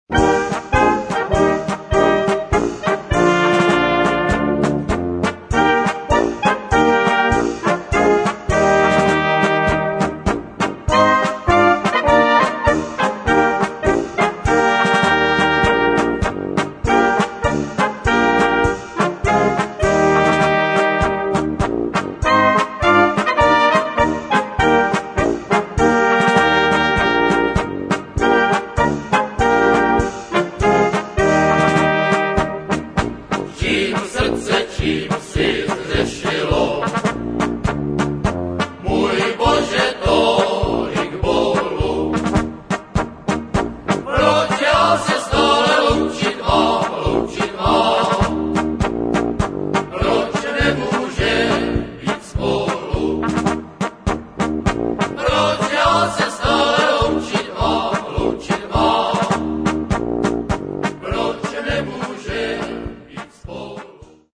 polka 6:47 upr.